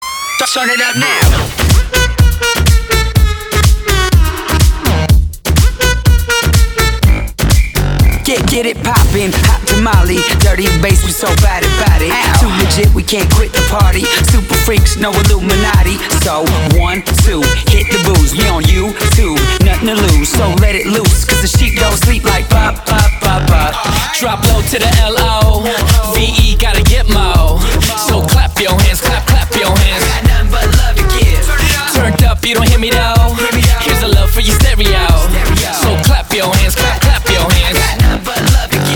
• Качество: 320, Stereo
ритмичные
мужской вокал
громкие
зажигательные
электронная музыка
electro hop
hip-house